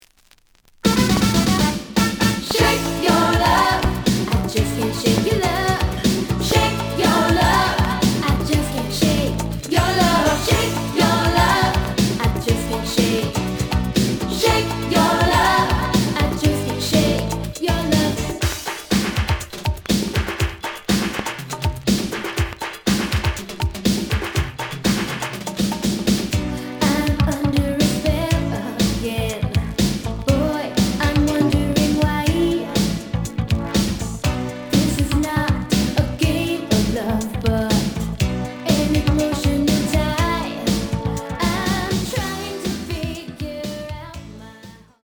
The audio sample is recorded from the actual item.
●Genre: Rock / Pop
Edge warp.